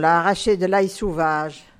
Localisation Sainte-Foy
Catégorie Locution